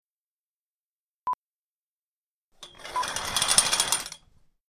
Drawing Bedroom Curtain, metal pole vsn 5 (sound fx)
Drawing bedroom curtain open or closed. Metal curtain rings over metal curtain rail. Scraping metal sound and metal rings. Some handling sounds of cloth curtain.
DrawingCurtains5_plip.mp3